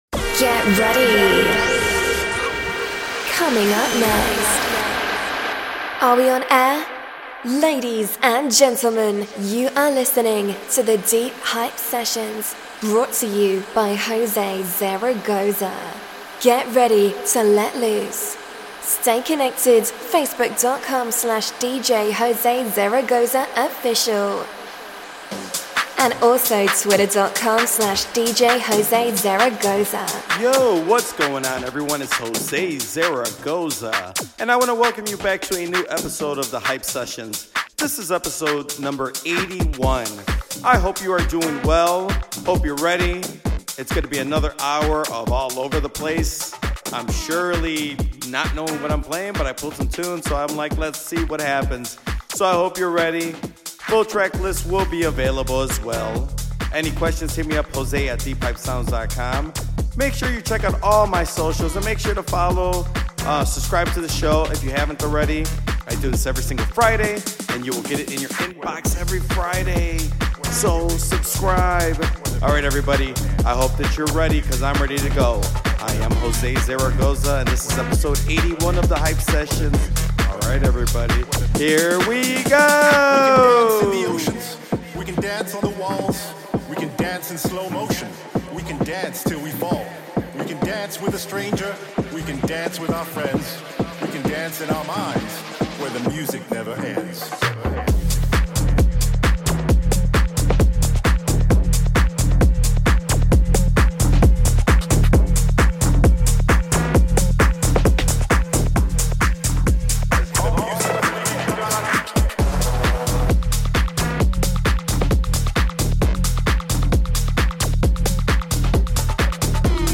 Another hour of fun music